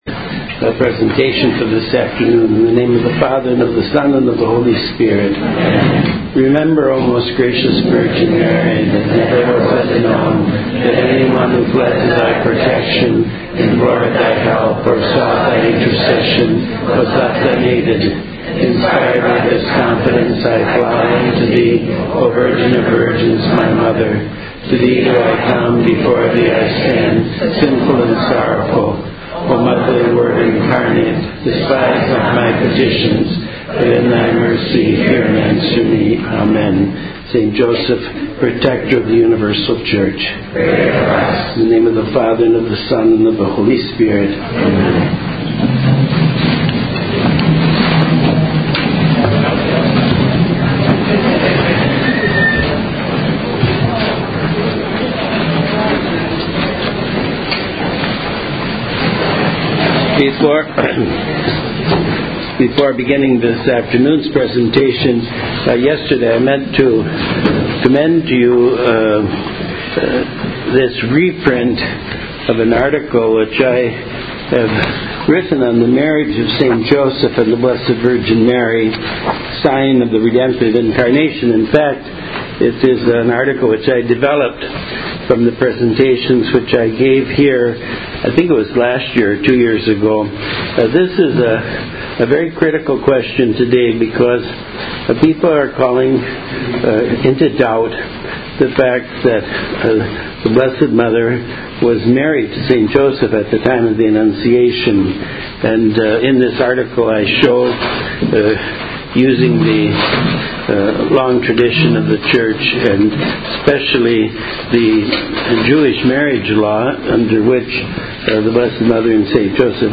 Audio presentations from 2019 Consecration Weekend.